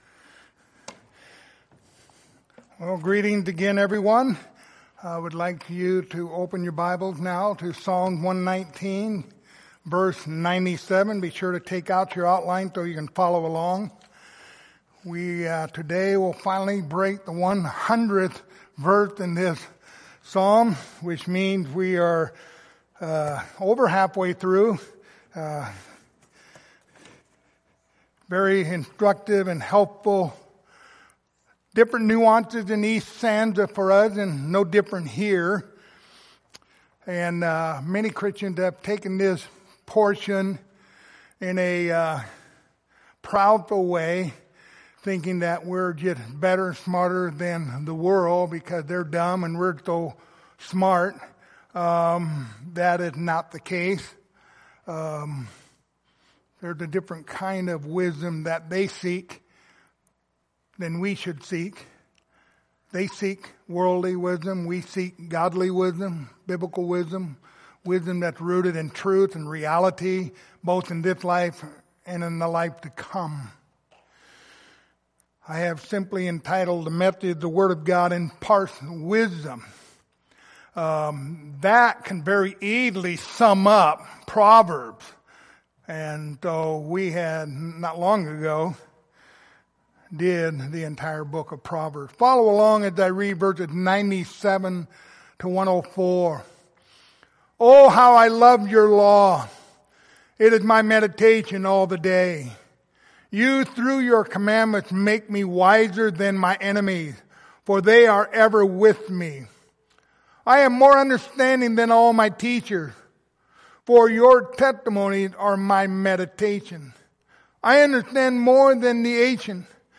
Passage: Psalm 119:97-104 Service Type: Sunday Morning